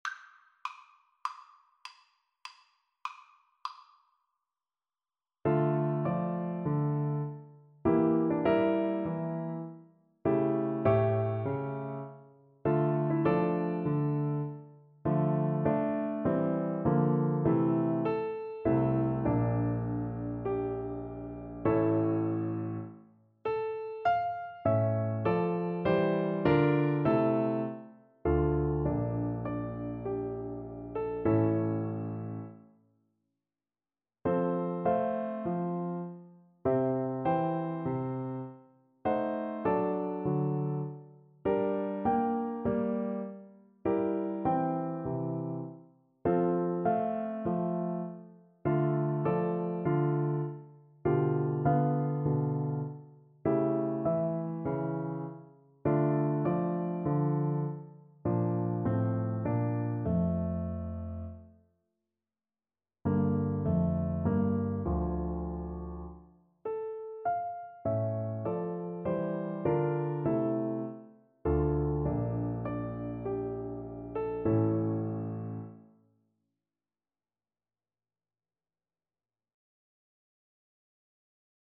Play (or use space bar on your keyboard) Pause Music Playalong - Piano Accompaniment Playalong Band Accompaniment not yet available transpose reset tempo print settings full screen
Largo =c.100
D minor (Sounding Pitch) (View more D minor Music for Trombone )
4/4 (View more 4/4 Music)
Classical (View more Classical Trombone Music)